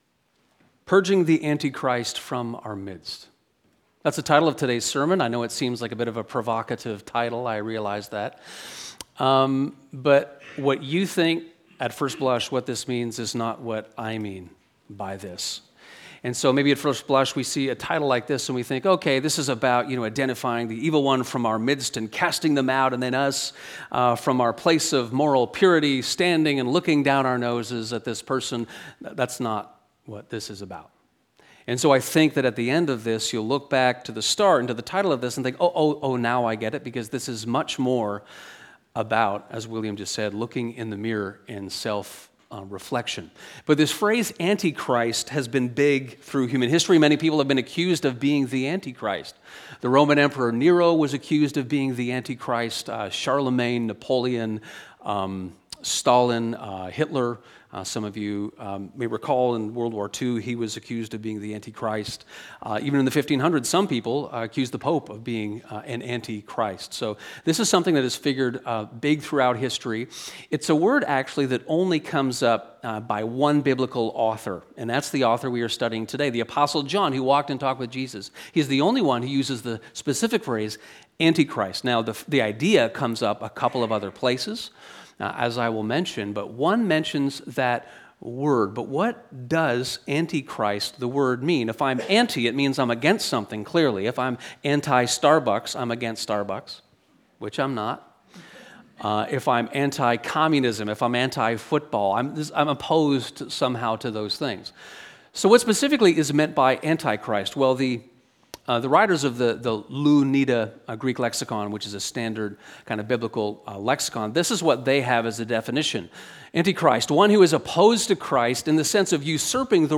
Purging the Antichrist From our Midst (Sermon)